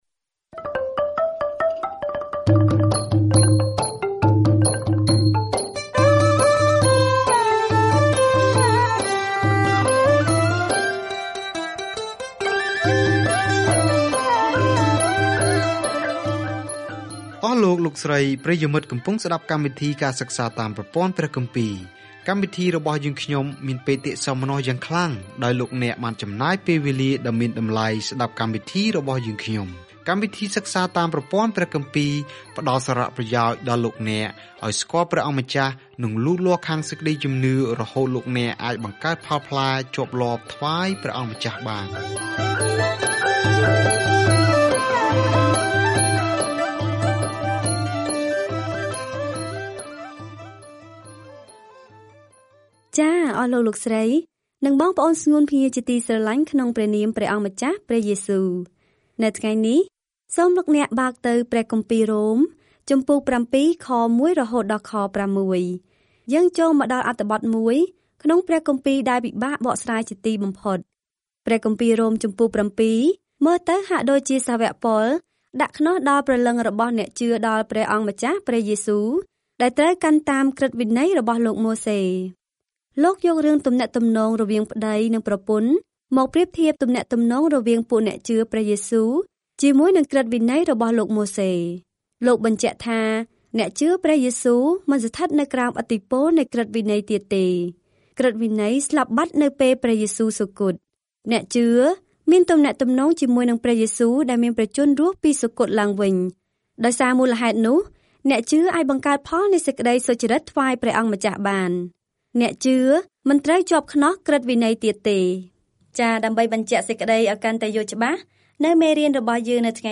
ការធ្វើដំណើរជារៀងរាល់ថ្ងៃតាមរយៈជនជាតិរ៉ូម ពេលអ្នកស្តាប់ការសិក្សាជាសំឡេង ហើយអានខគម្ពីរដែលជ្រើសរើសពីព្រះបន្ទូលរបស់ព្រះ។